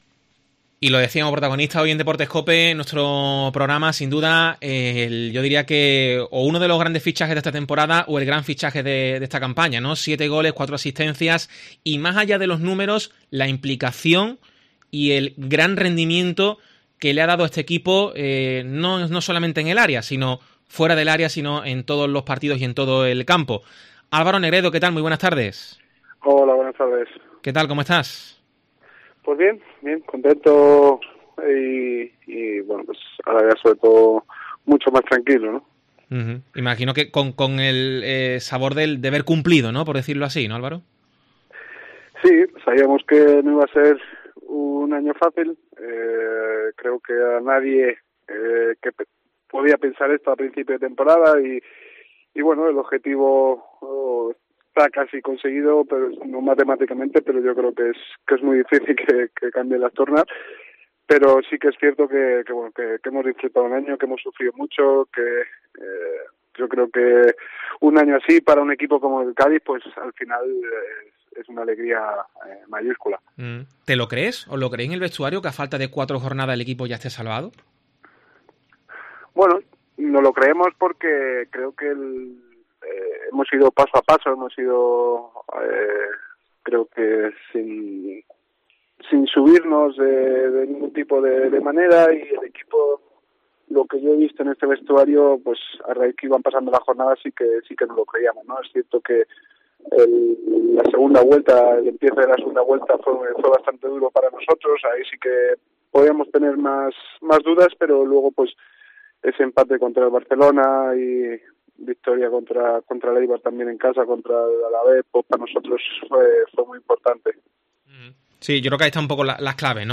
Álvaro Negredo analiza la temporada y su rendimiento en el Cádiz en Deportes COPE Cádiz